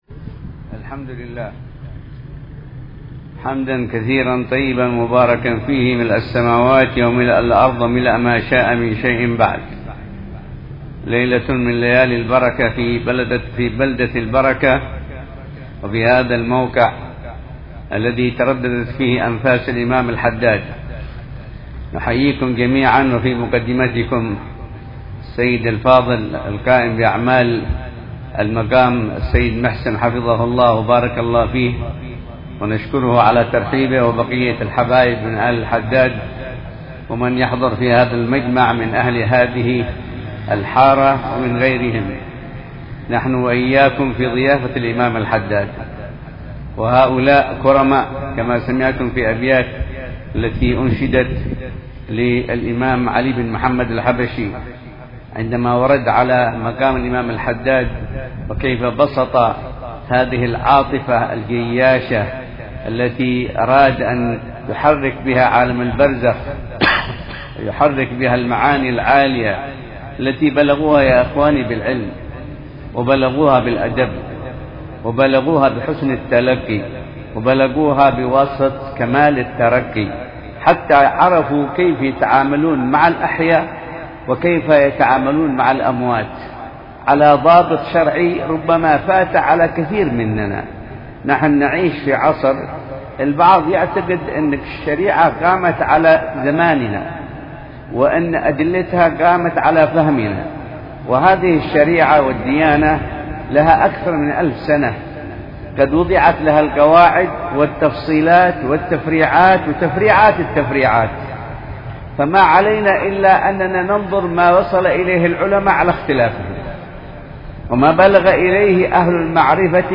بساحة مقام الإمام الحداد بحاوي الخيرات – تريم – حضرموت